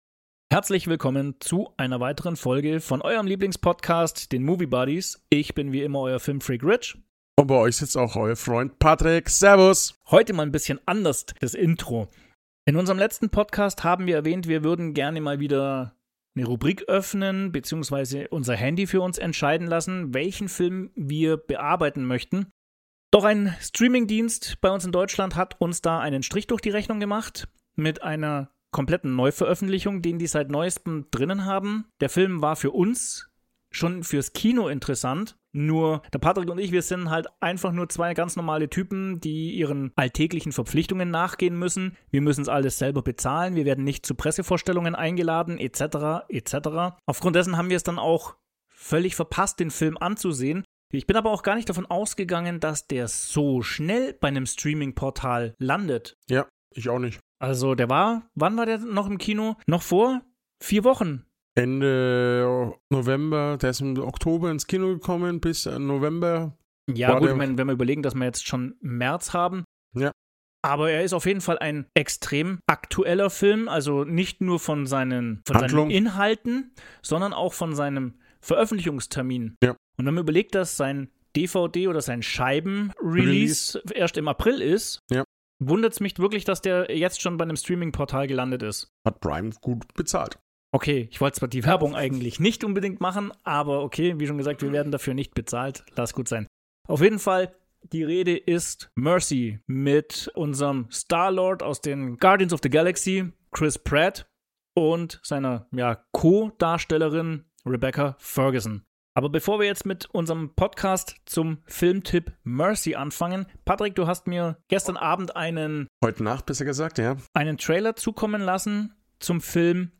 Erlebt eine spannende und tiefgründige Diskussion über KI und ihren Einsatz in unserer Gegenwart und in der Zukunft.